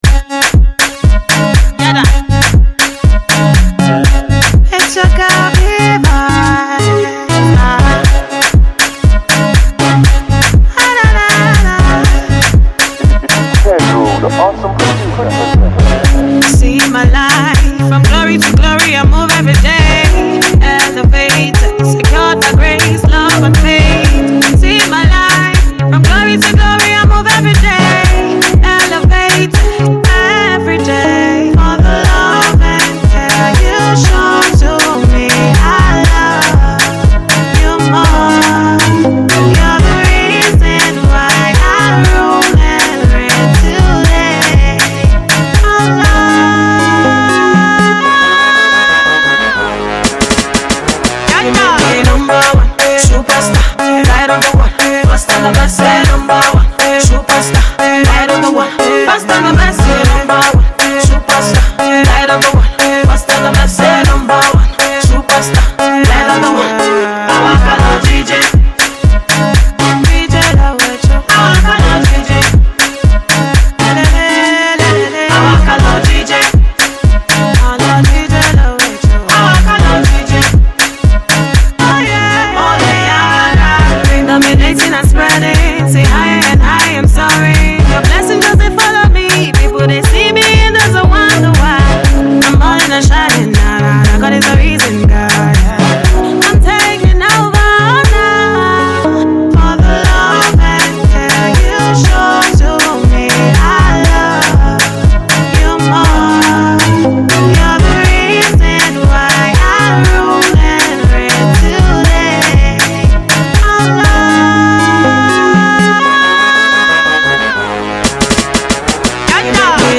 hit single